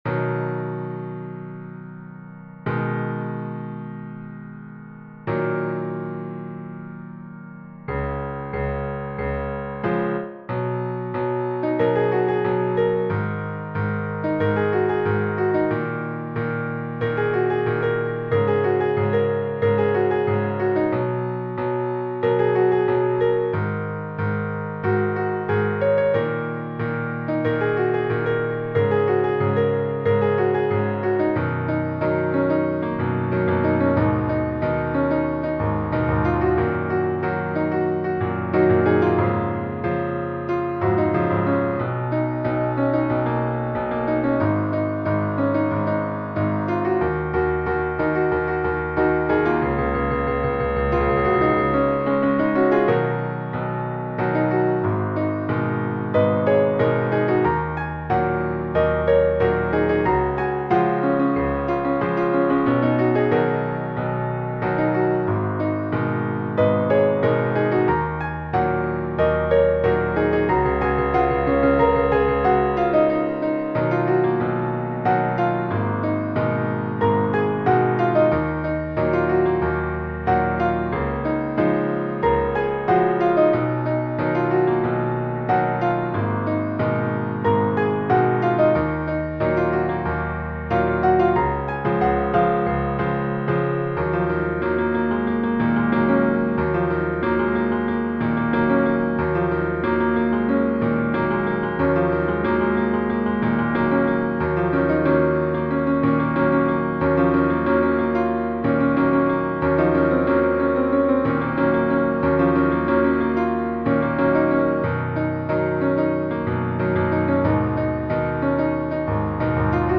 本首曲子是中等难度的C#/Db钢琴歌谱（带和弦）